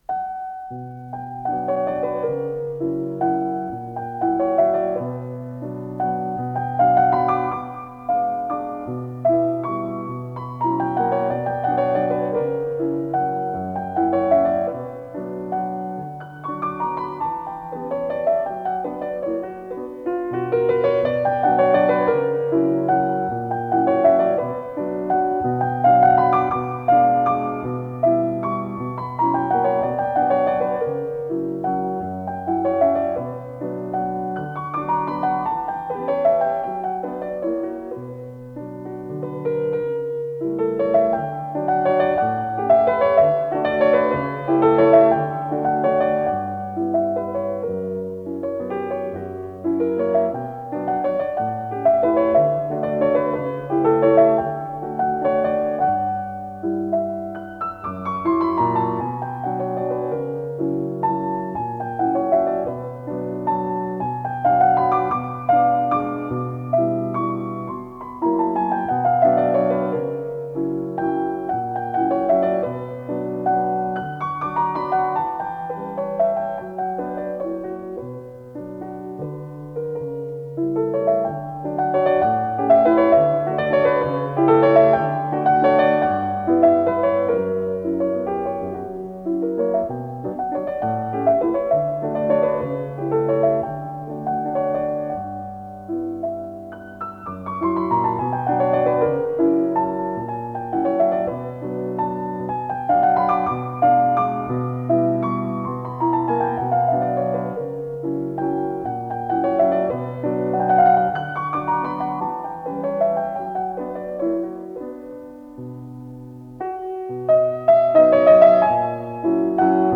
с профессиональной магнитной ленты
ПодзаголовокСоч. 69 №2, си минор
фортепиано
ВариантДубль моно